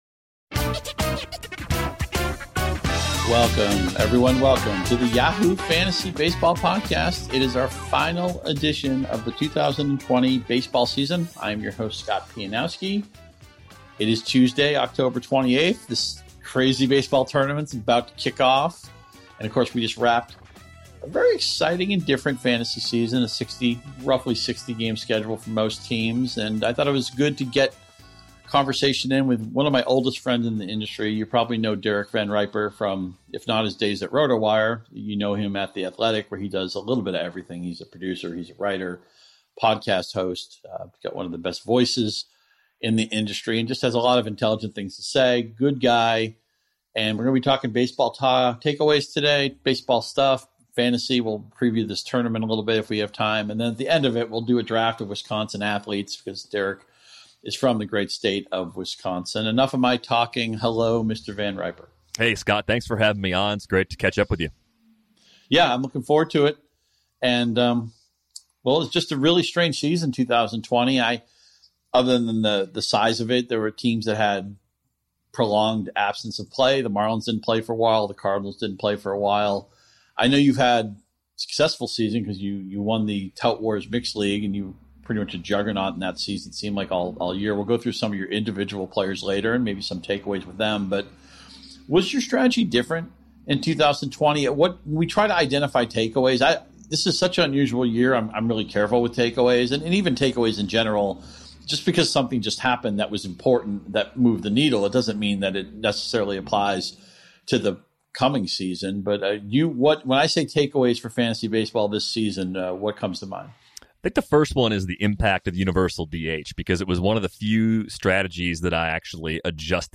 pundits and Yahoo Sports' cast of experts for a lively, informative and not-too-serious weekly discussion on fantasy strategy and the major happenings in the world of baseball.